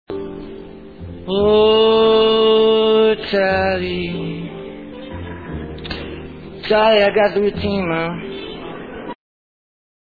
Bobby also demonstrated his versatility in this hour with his singing, impressions, acting and playing the guitar and piano.